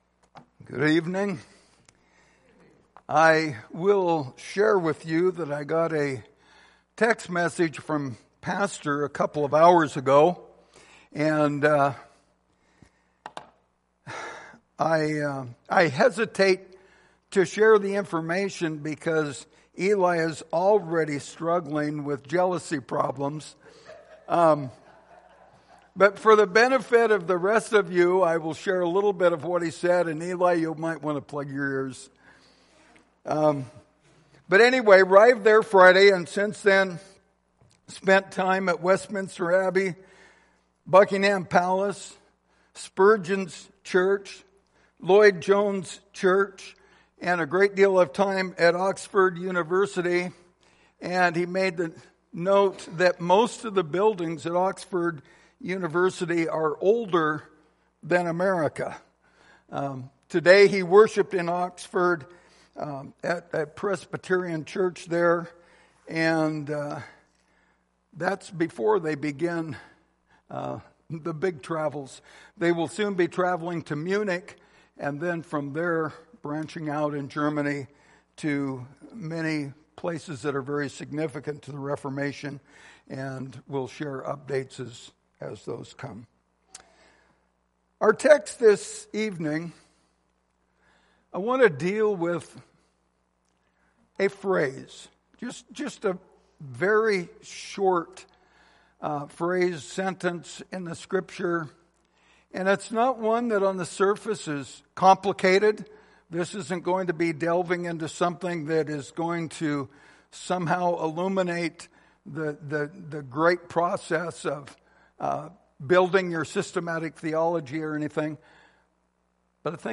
Lord's Supper Passage: Matthew 11:28 Service Type: Lord's Supper Topics